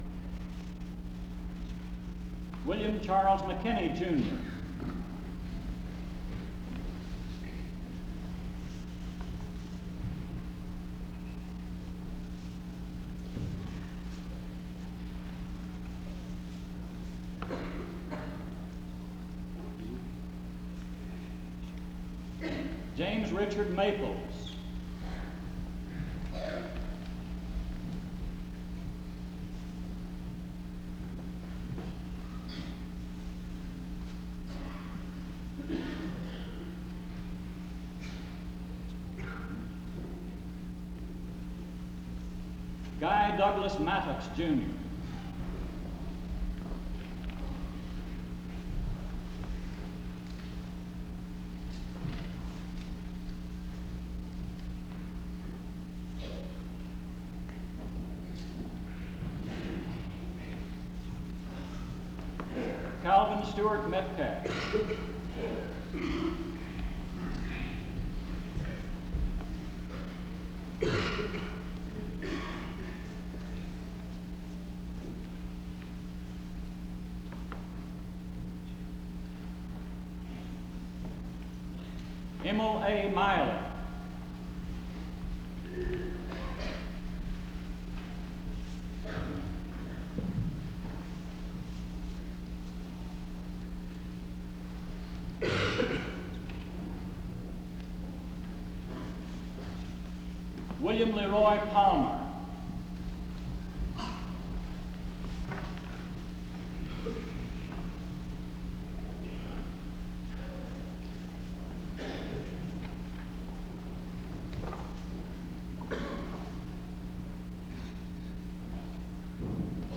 SEBTS Commencement